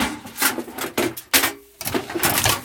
weaponsafe2.ogg